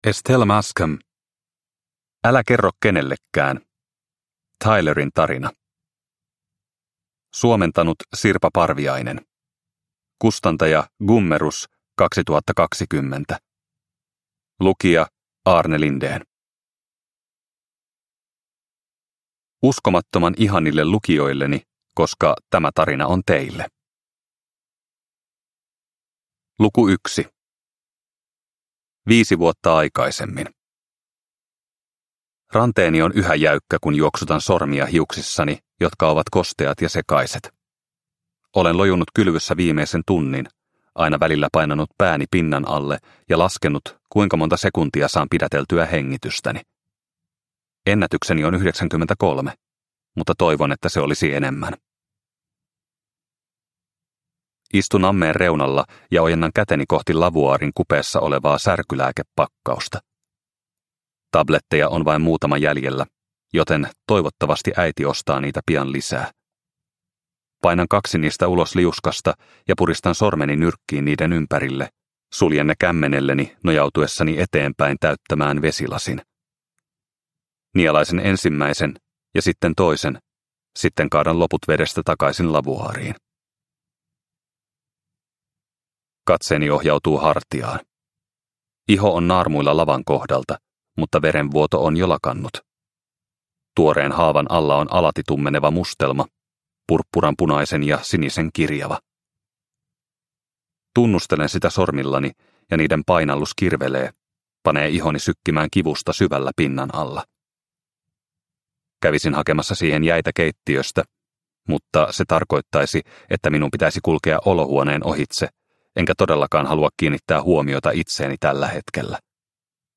Älä kerro kenellekään – Ljudbok – Laddas ner